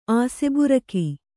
♪ āseburaki